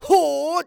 xys发力6.wav
xys发力6.wav 0:00.00 0:00.67 xys发力6.wav WAV · 58 KB · 單聲道 (1ch) 下载文件 本站所有音效均采用 CC0 授权 ，可免费用于商业与个人项目，无需署名。
人声采集素材